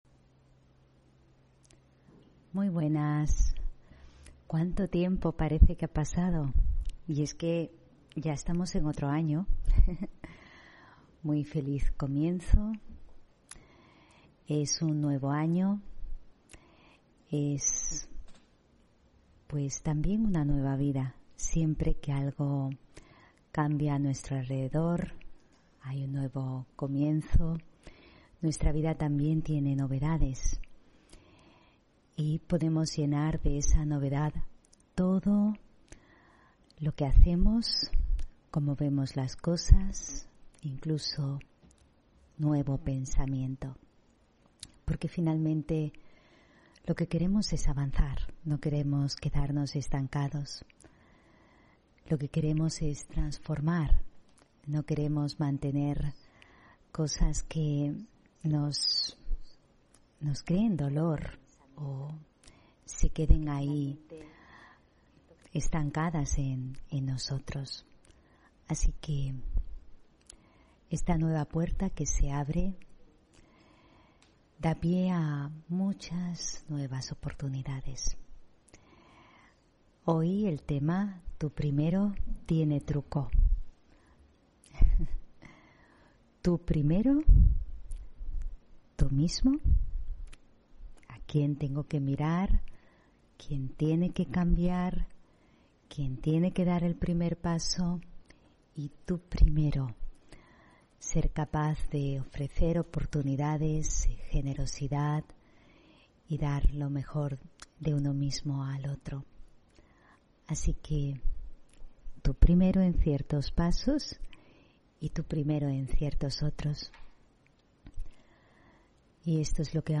Meditación y conferencia: Tú primero (5 Enero 2022)